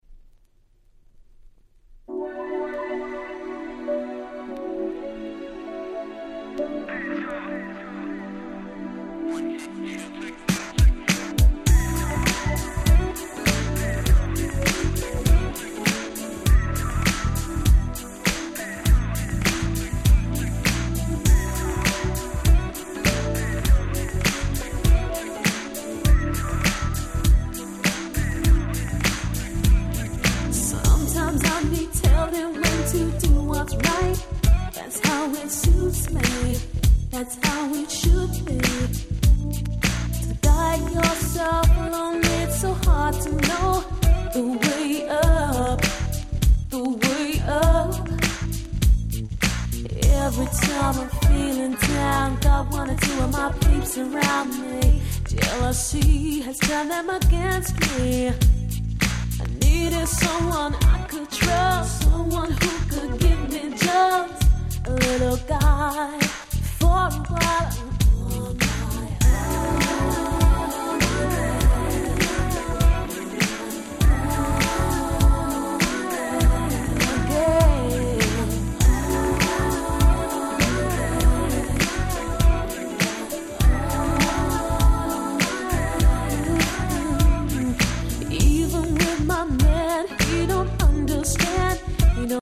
95' NiceマイナーUK R&B !!